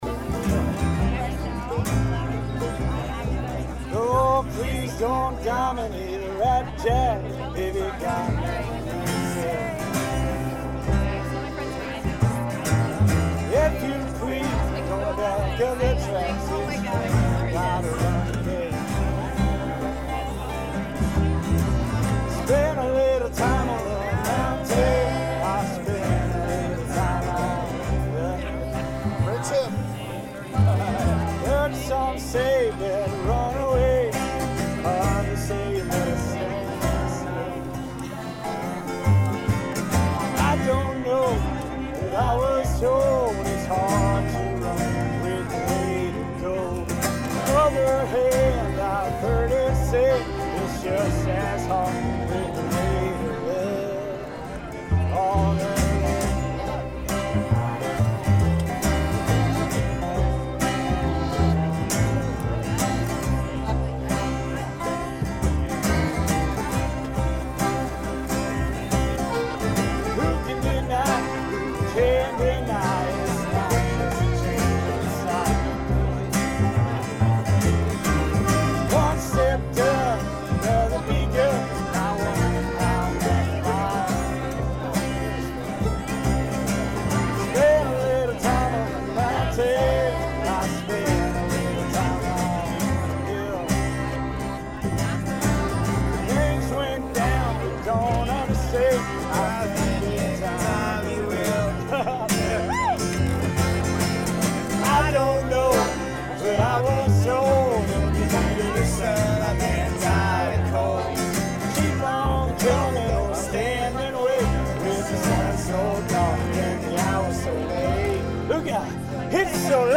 We crossed paths and setup the jam right where we met at the crossroads by the "top of the world" bus.
The crowd began to form and before long we were stirring up folk roots with the aid of the players in the campground!
and many others on banjo, mandolin, fiddle, guitars, and so on...Please email with any more information you may have to add...